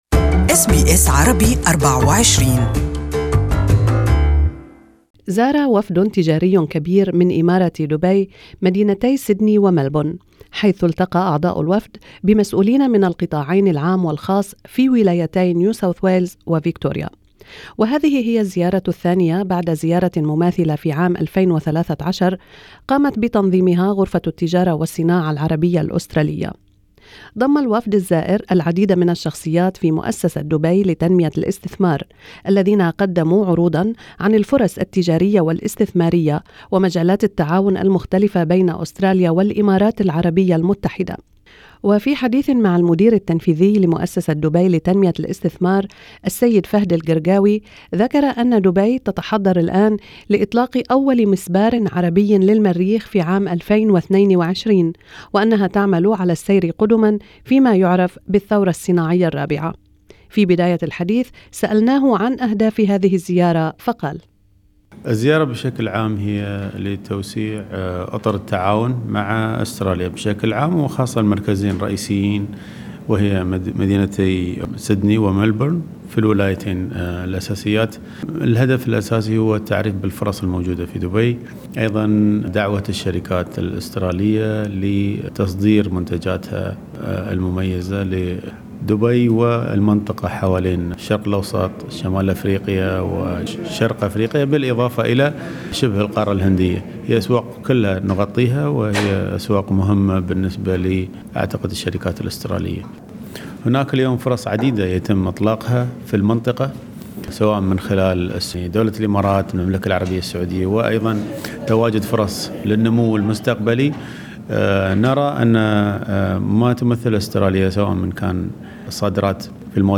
SBS Arabic24 interviewed the CEO of Dubai FDI Mr Fahad Al Gergawi who spoke about more opportunities for Australians to do business in Dubai and the region.